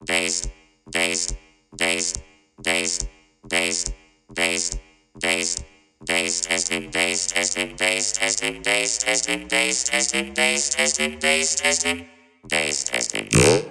标签： 140 bpm Dubstep Loops Vocal Loops 2.31 MB wav Key : Unknown FL Studio
声道立体声